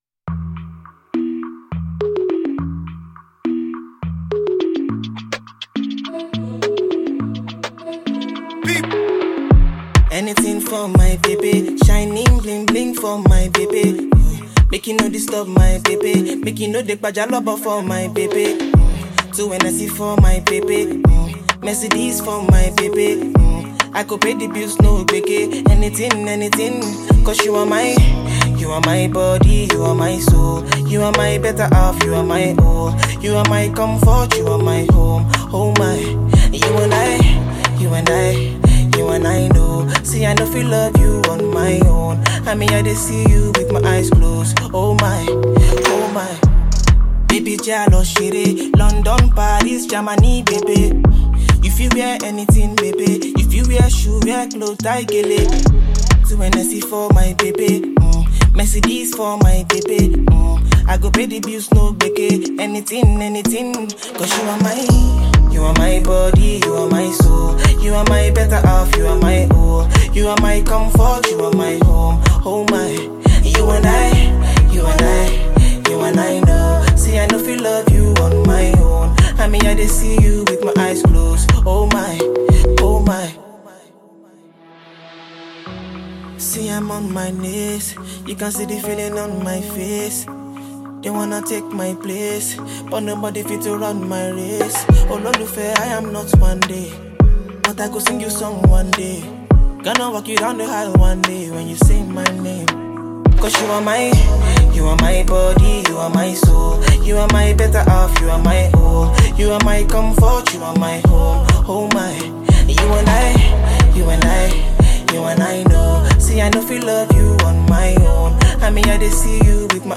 thrilling new gbedu song